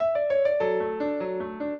piano
minuet11-8.wav